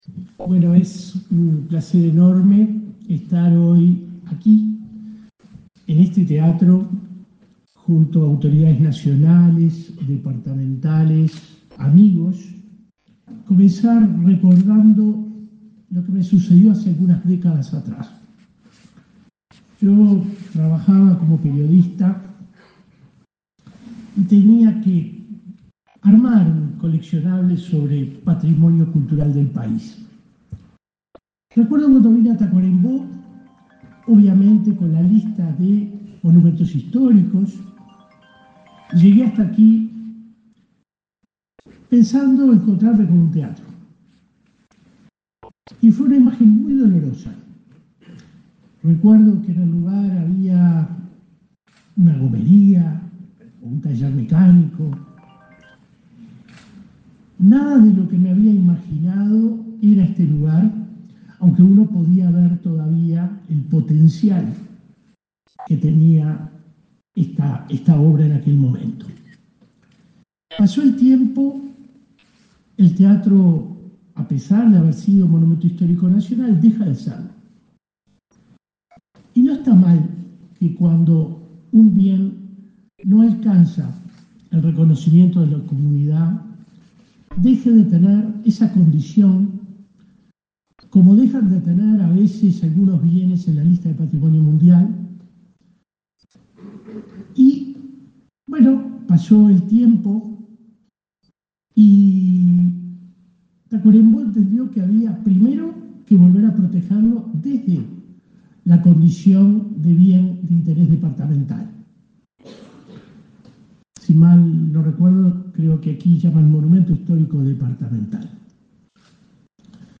MEC declaró al teatro Escayola como Monumento Histórico Nacional 11/09/2024 Compartir Facebook X Copiar enlace WhatsApp LinkedIn El Ministerio de Educación y Cultura (MEC) y la Comisión del Patrimonio Cultural de la Nacion realizaron un acto, este 11 de setiembre, por la declaratoria del teatro Escayola en Tacuarembó como Monumento Histórico Nacional. Participaron del evento el ministro Pablo da Silveira; el presidente de la Comisión de Patrimonio, William Rey, y el intendente departamental, Walter Exquerra.